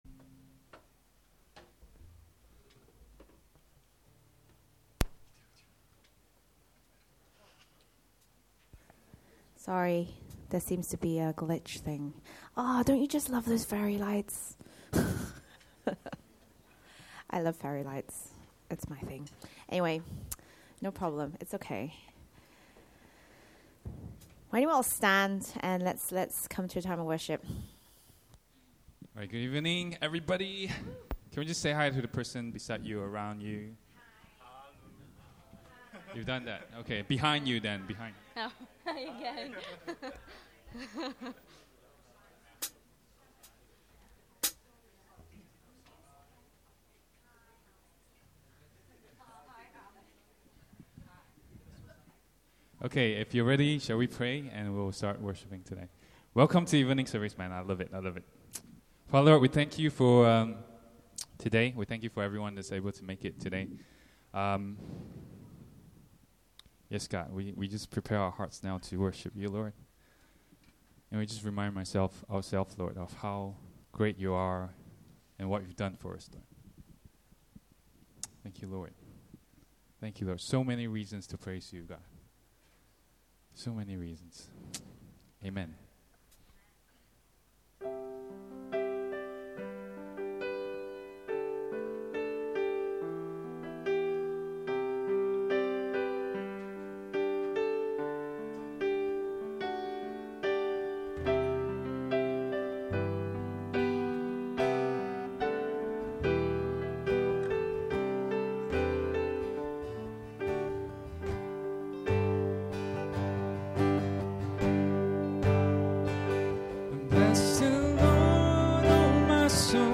Worship October 28, 2018